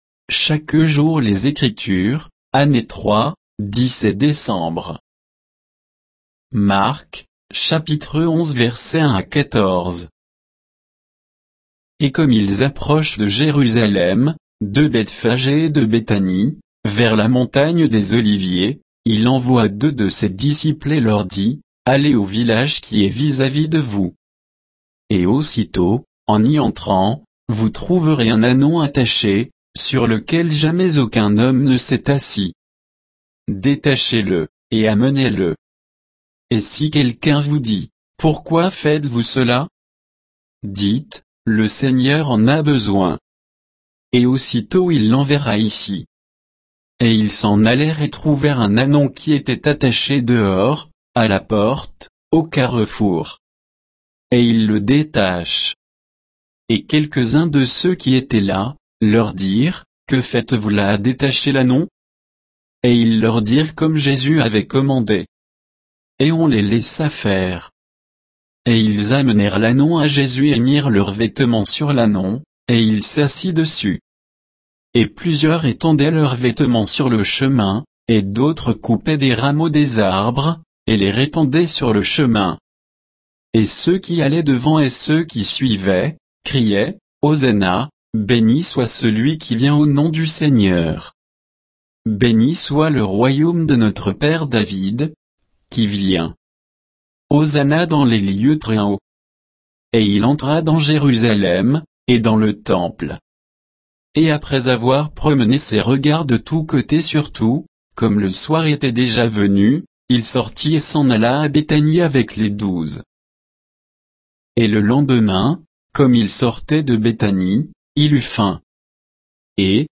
Méditation quoditienne de Chaque jour les Écritures sur Marc 11